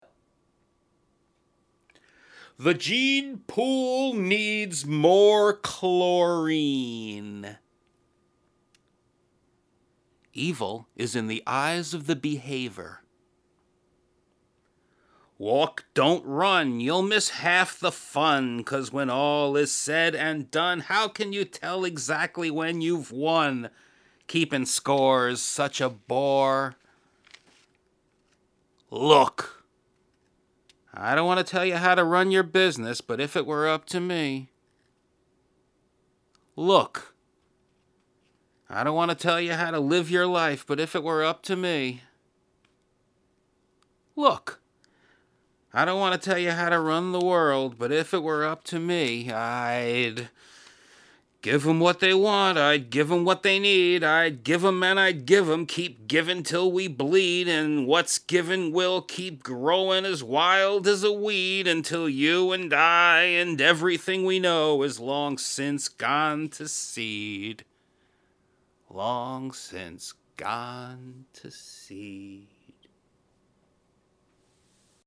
- Spoken word - previously unreleased